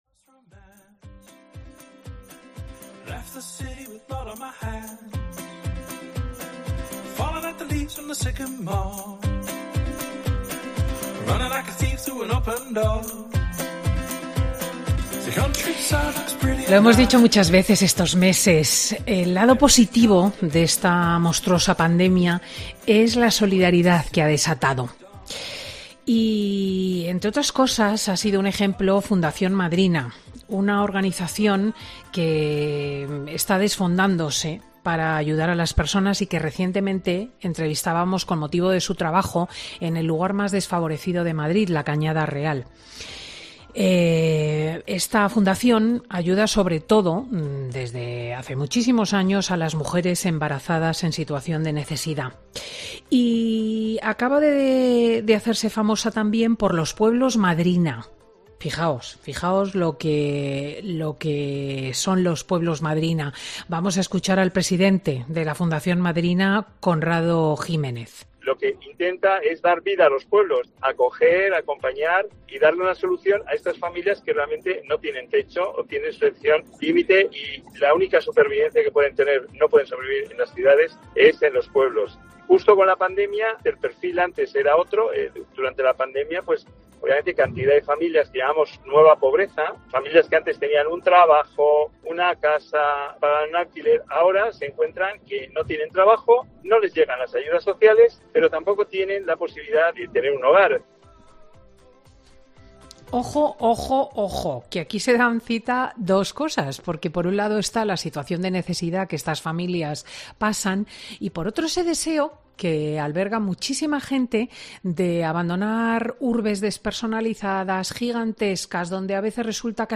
AUDIO: Dos parejas cuentan en Fin de Semana con Cristina cómo la Fundación Madrina les ha ayudado a salir de una situación de riesgo extremo de...